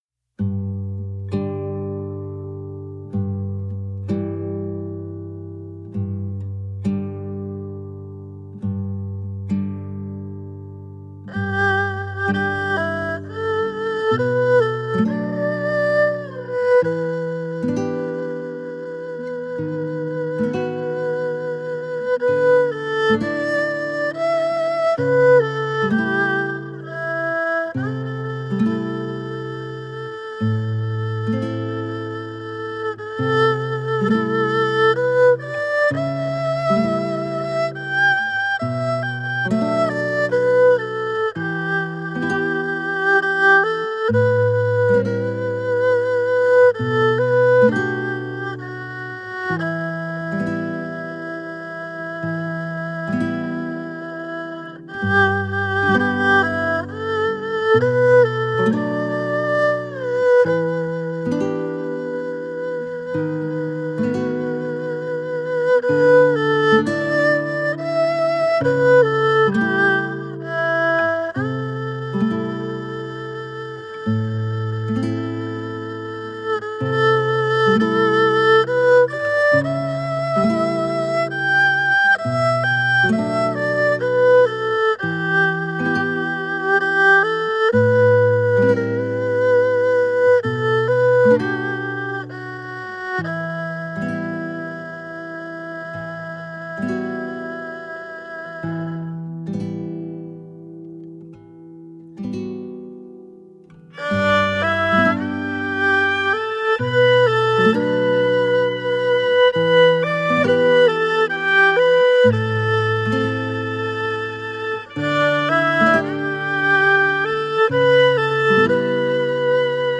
The album was recorded in Shanghai.
Tagged as: World, Instrumental World, Folk